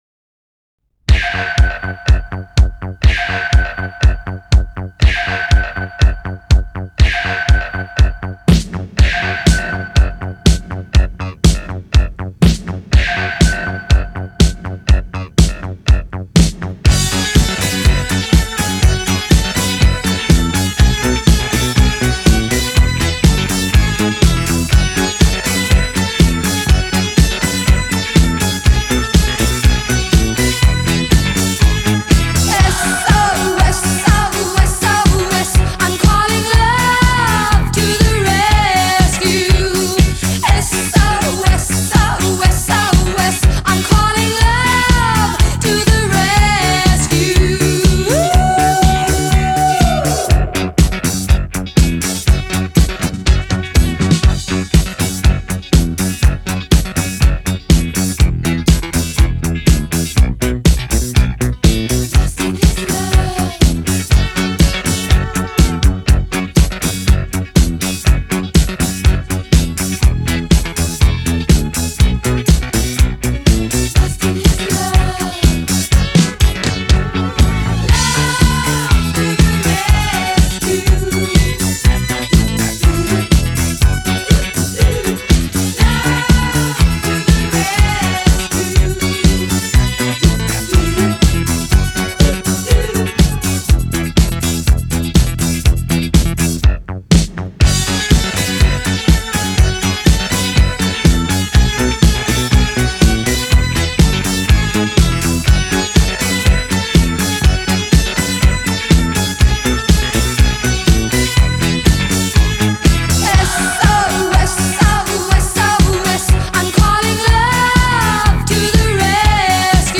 Ошеломляющий красоты и силы голос и сполнение - супер!